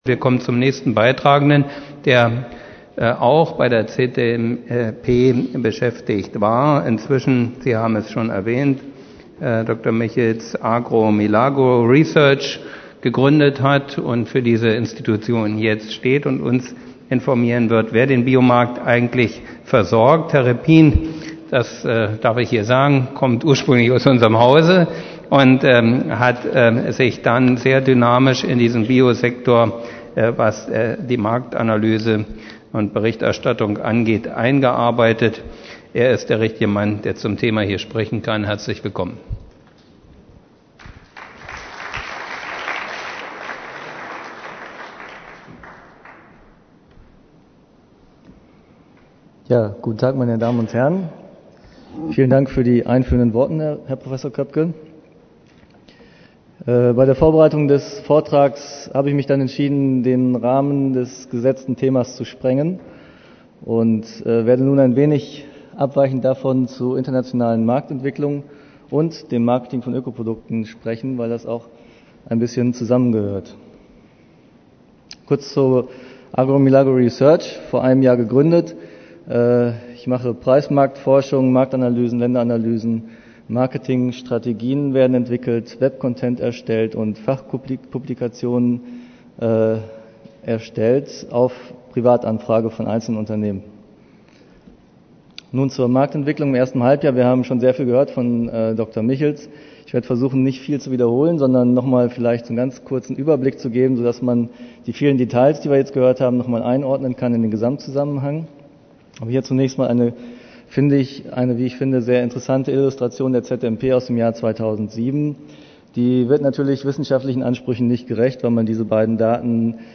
auf dem 3. Bio Handels-Forum